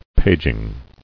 [pag·ing]